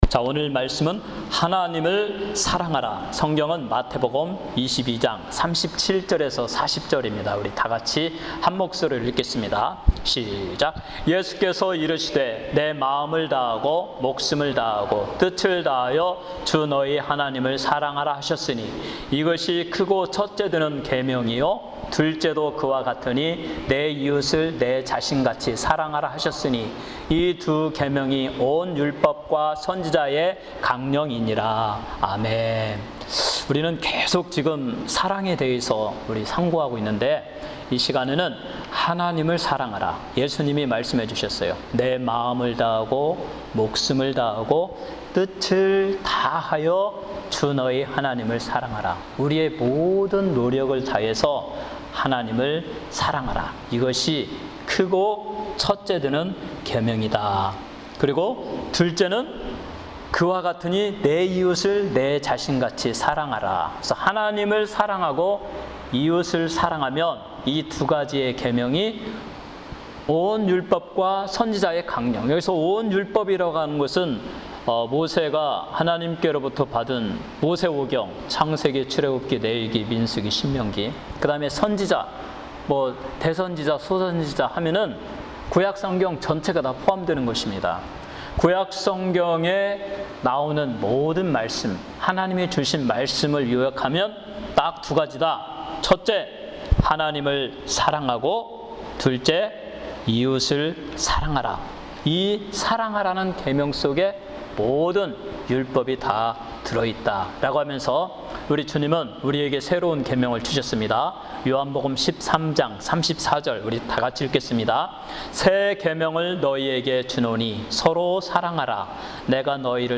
주일 설교.